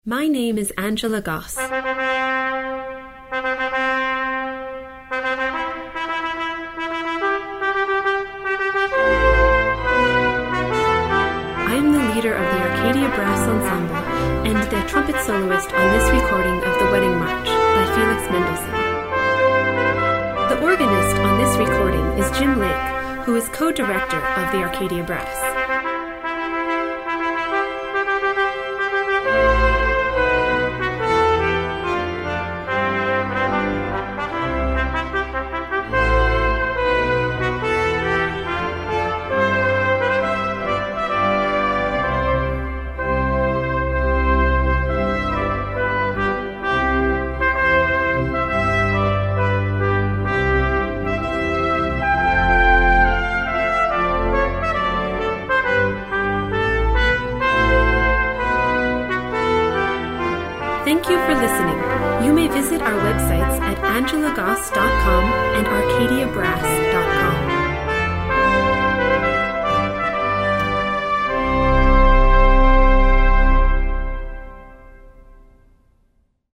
recessional (mp3) is an energetic burst of glorious sound.